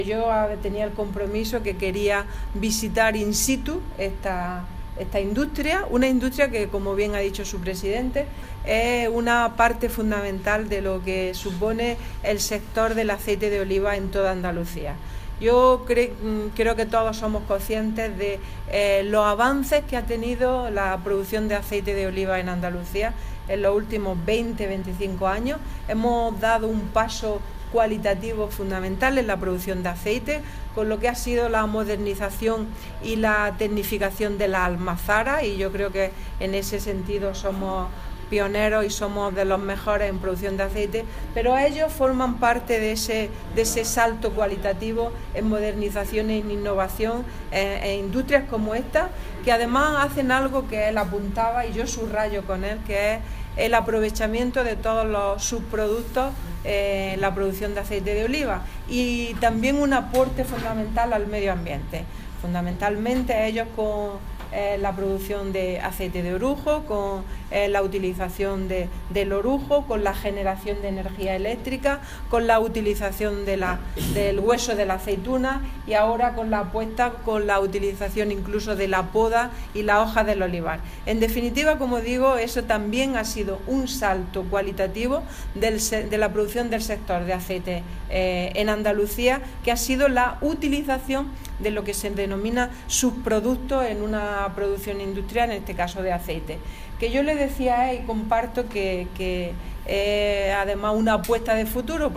Declaraciones consejera oleícola El Tejar